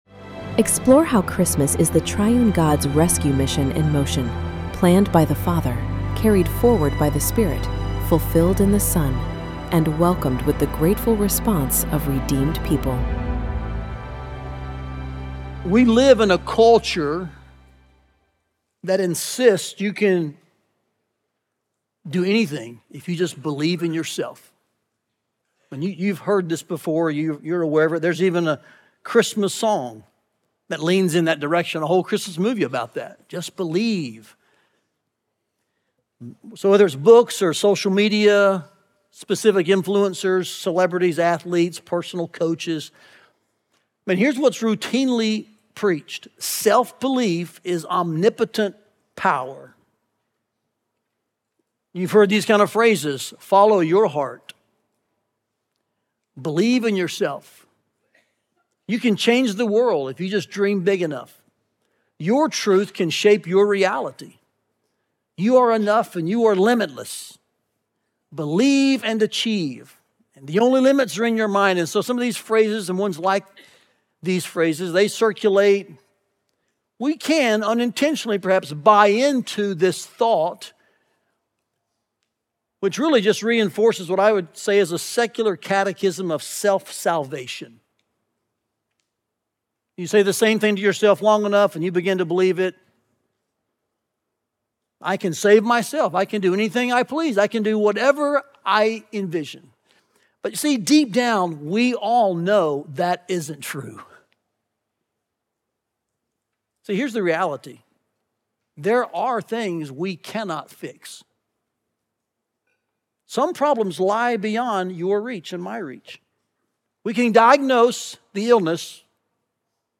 Listen to the second sermon from Advent 2025 and learn more about our Advent series here.